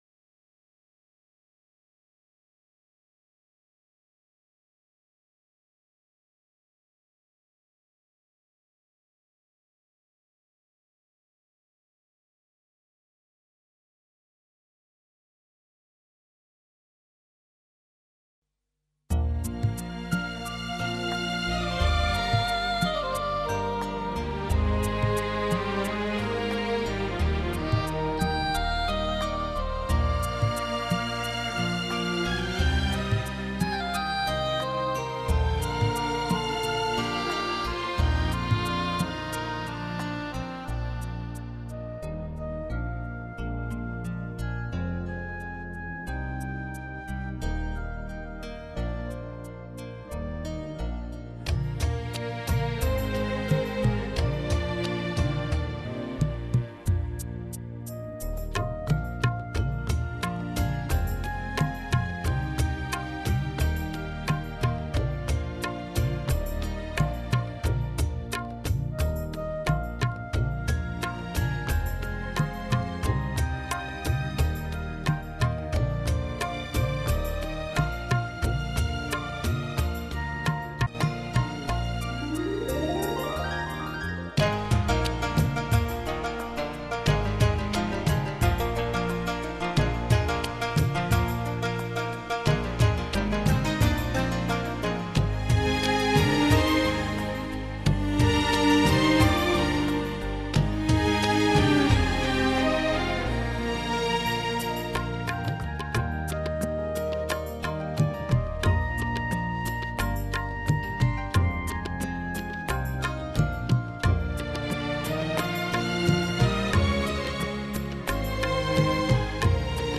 Artist: Instrumental